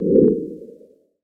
Download Sfx Scene Change Whoosh sound effect for video, games and apps.
Sfx Scene Change Whoosh Sound Effect
sfx-scene-change-whoosh-2.mp3